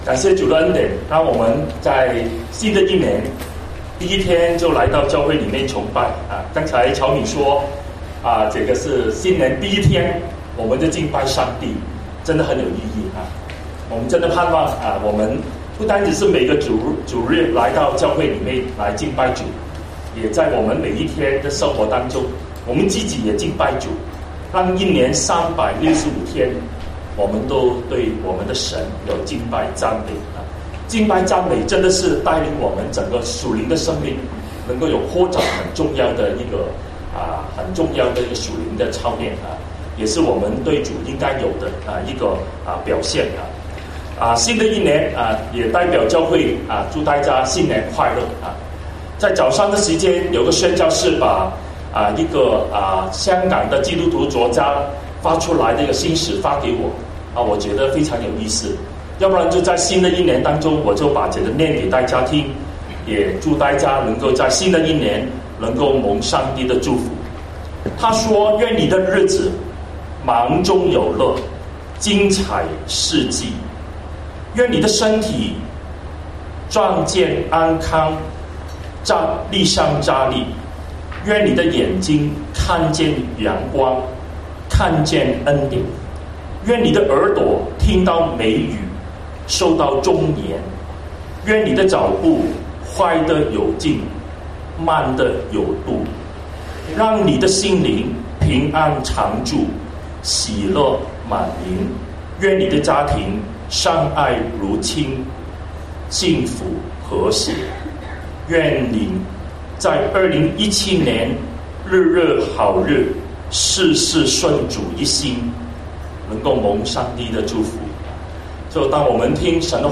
牛頓粵語崇拜 , 講道